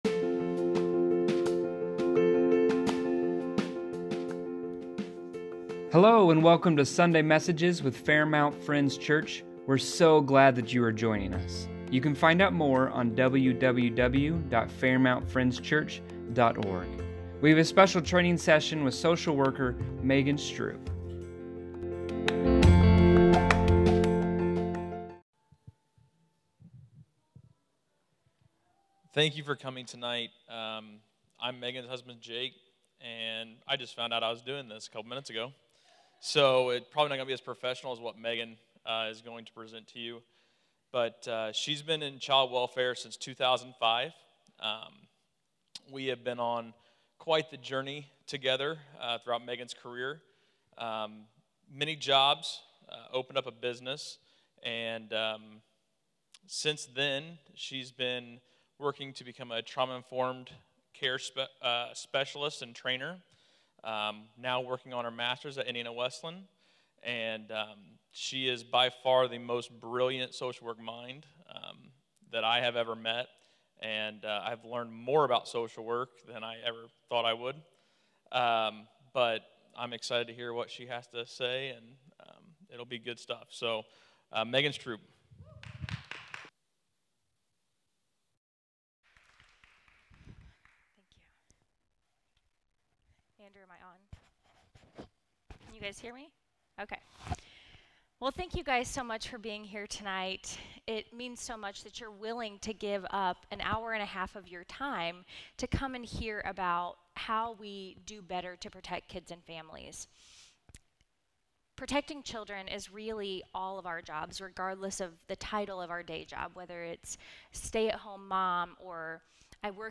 Community Child Abuse Awareness Training - 9/24/2019
a Social Worker